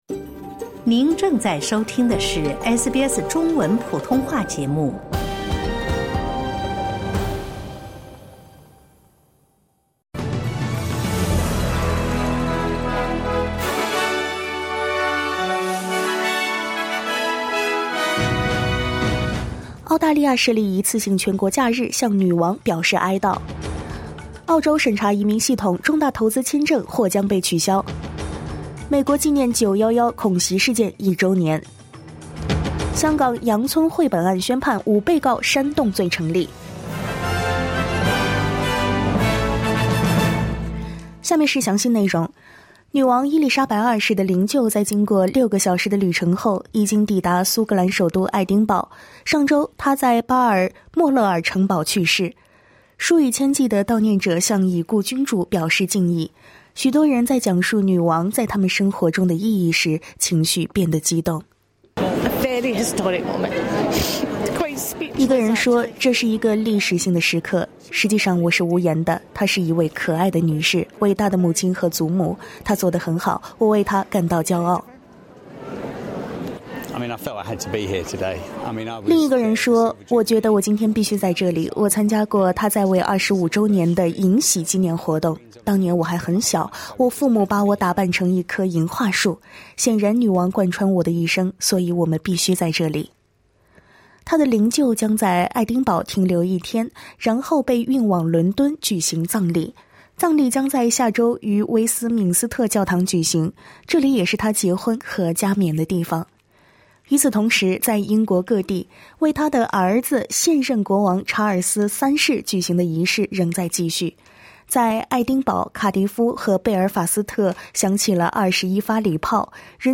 SBS早新闻（9月12日）